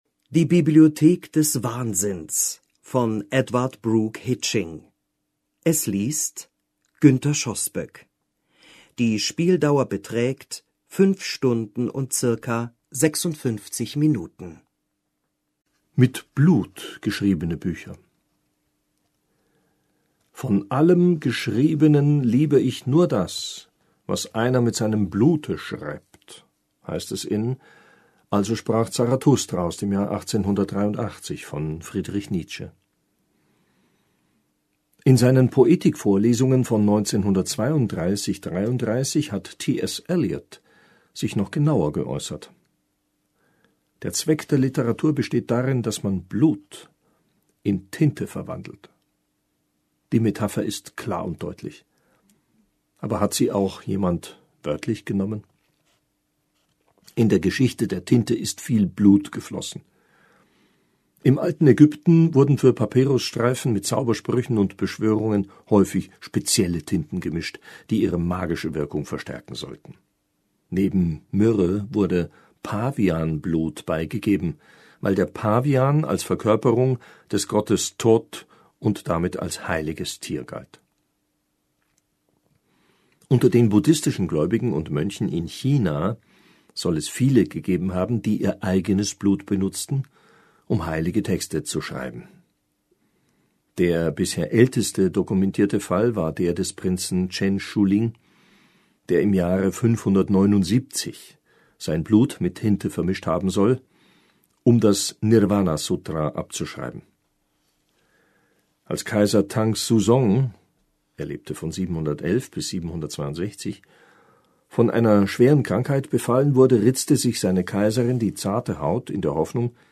liest diese Reise durch die Geschichte der Literatur: